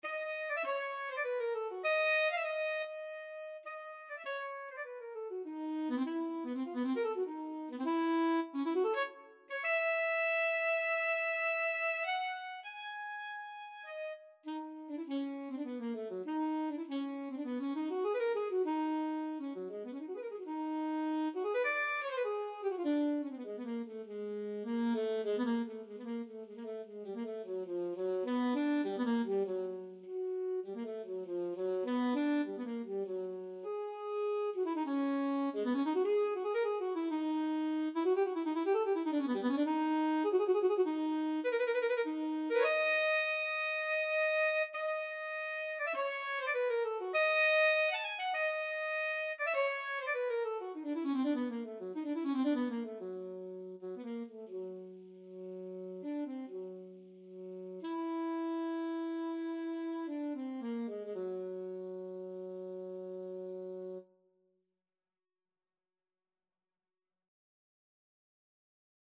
Alto Saxophone version
3/4 (View more 3/4 Music)
Trs modr
Saxophone  (View more Advanced Saxophone Music)
Classical (View more Classical Saxophone Music)